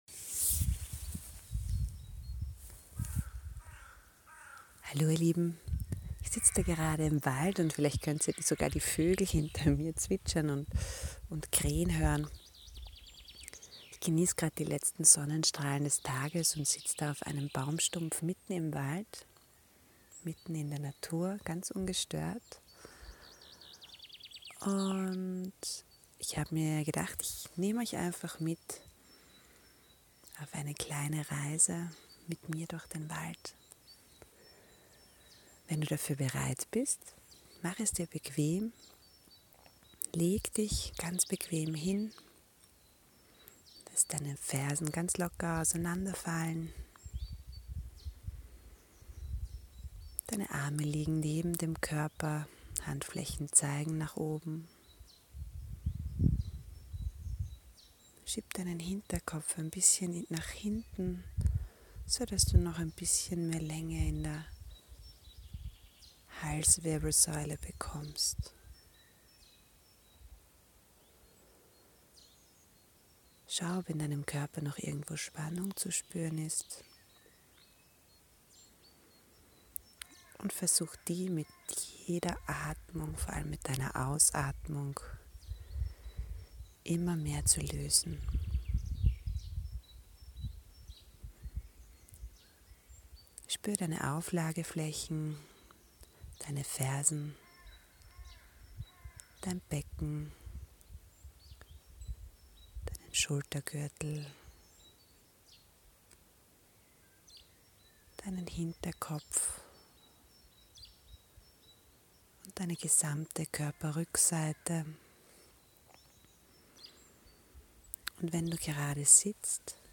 Ich setzte mich genoss für ein paar Momente die Sonnenstrahlen und nahm dann ganz spontan mein Handy zur Hand, und habe für EUCH die folgende Entspannungsreise in dieser bezaubernden STIMMUNG aufzunehmen, es war weder geplant, noch vorbereitet, es ist einfach entstanden da ich so ein starkes Bedürfnis hatte diesen wunderSCHÖNEN Moment teilen zu wollen....